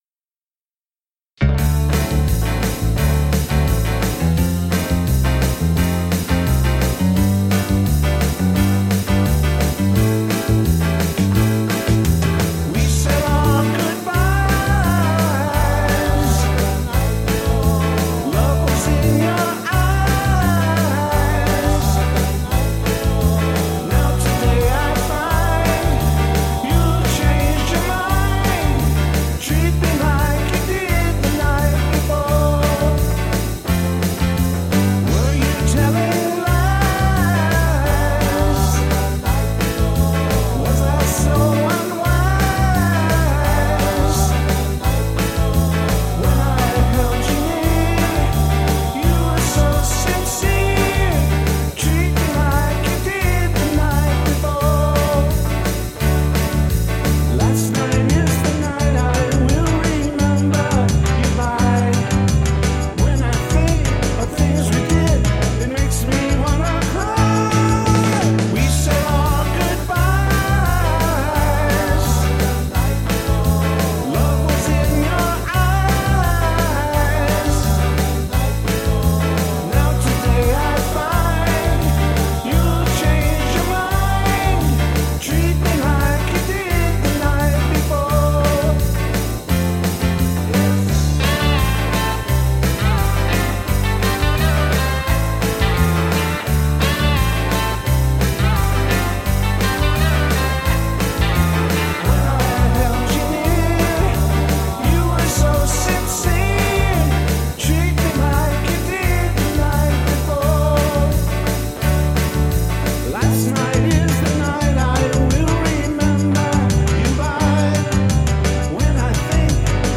- Classic Rock Covers -
All these songs were recorded in my basement
These are unmastered headphone mixes, and